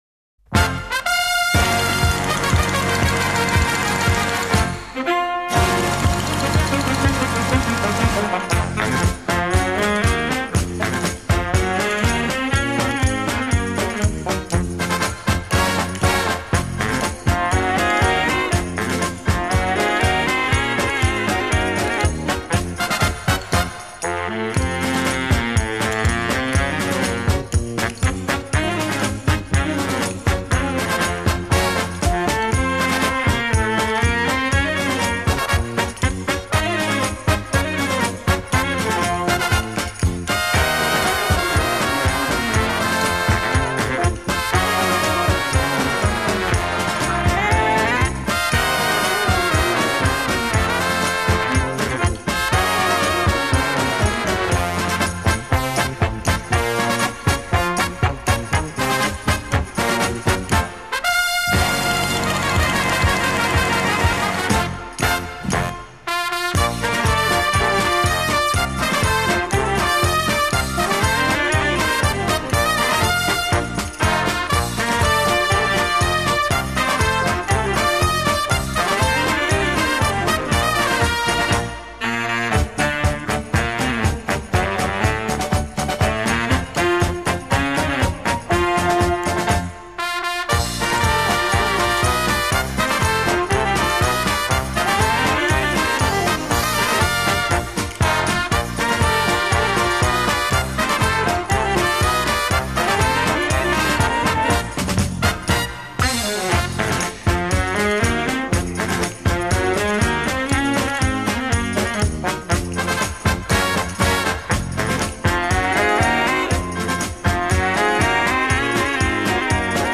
01 Paso Double